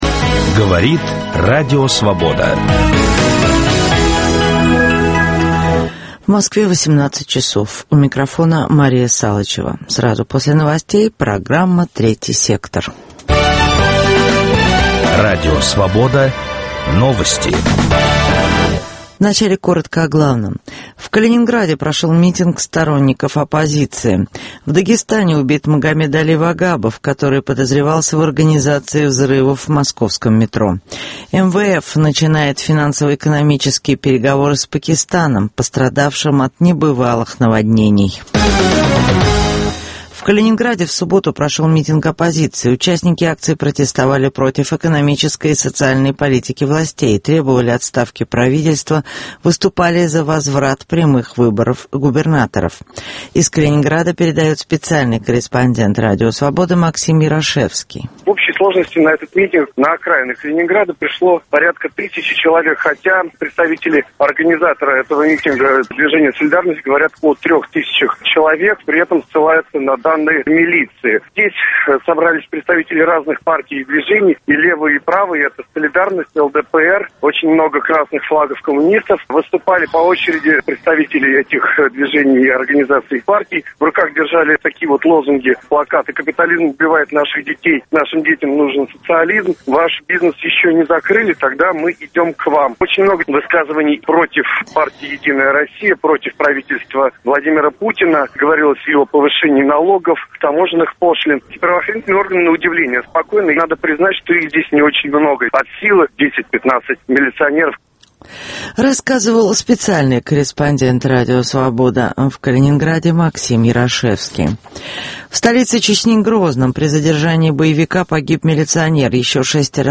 Гости программы «Третий сектор»: российские атомщики и экологи, немецкие политики и петербургские правозащитники.